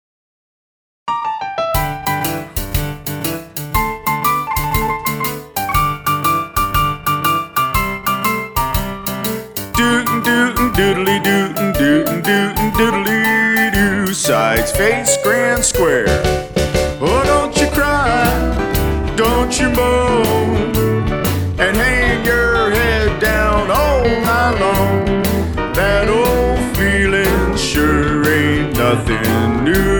Singing Call
Voc